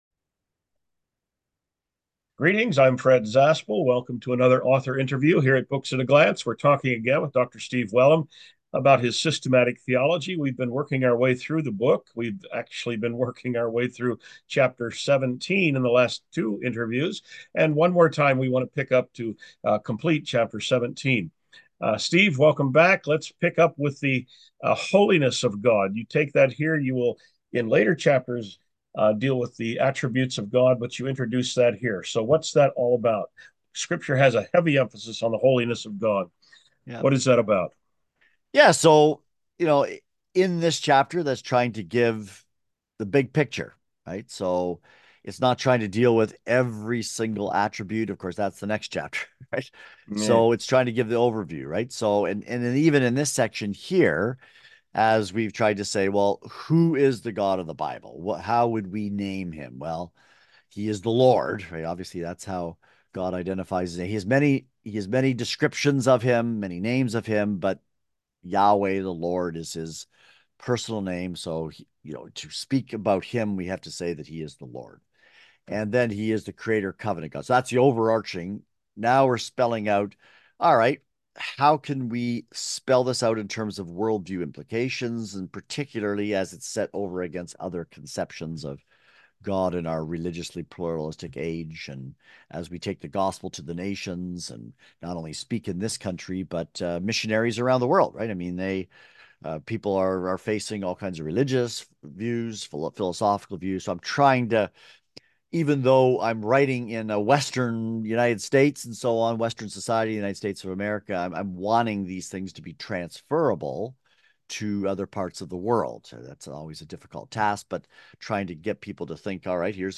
An Author Interview from Books At a Glance
Sample Audio Interview: